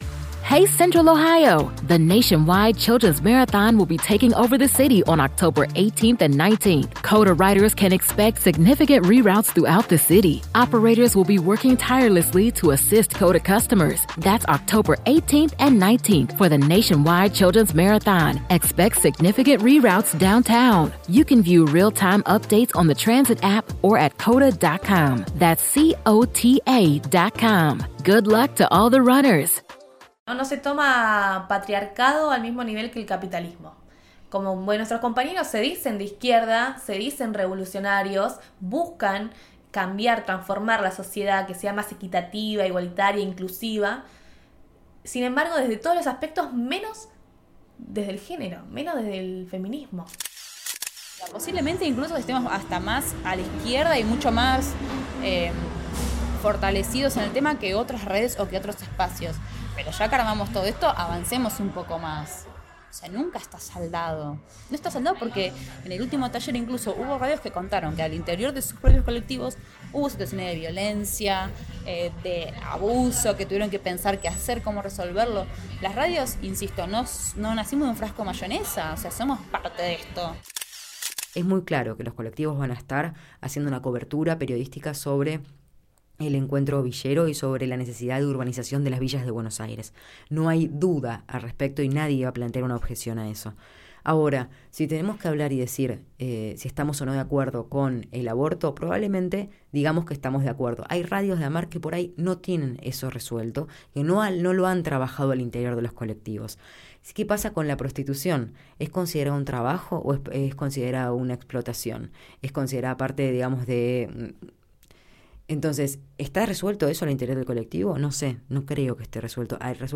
Informe radiofónico que analiza la relevancia que se le da a los temas de géneros en la Asociación Mundial de Radios Comunitarias - AMARC Argentina.